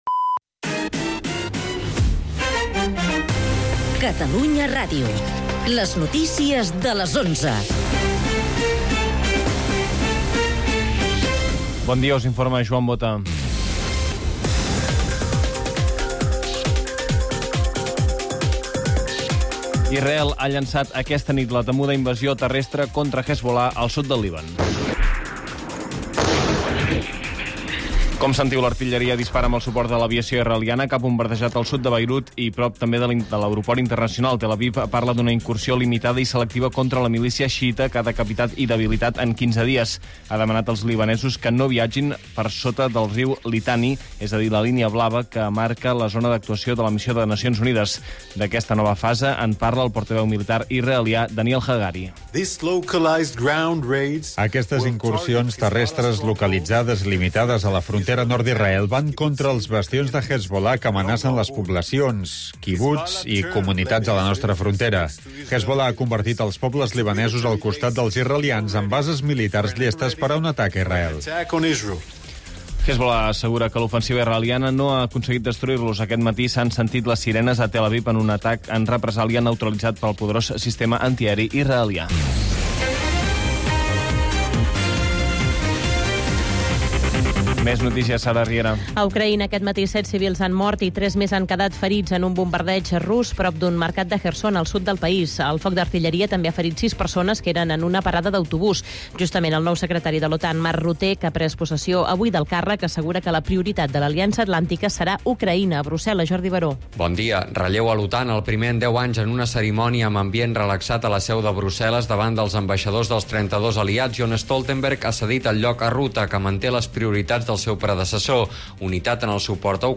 El mat, d'11 a 12 h (entrevista i humor) - 01/10/2024